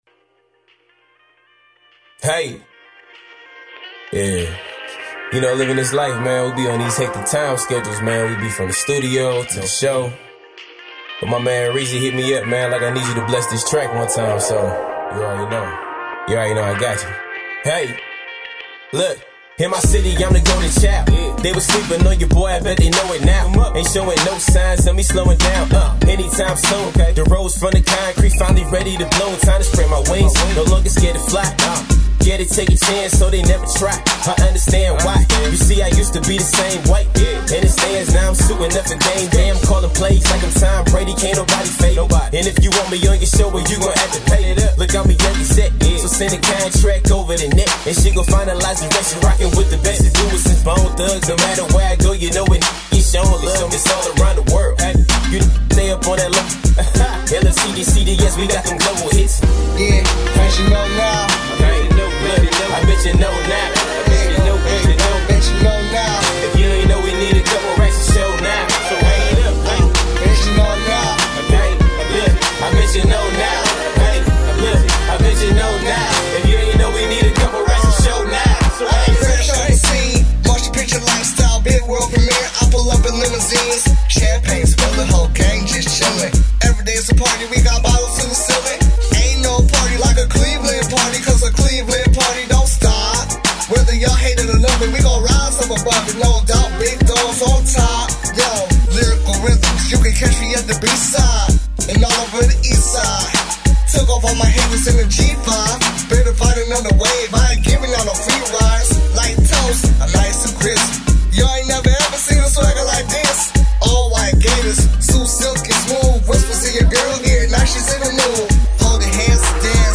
Hiphop
A Smooth Hip-Hop Record for all audiences.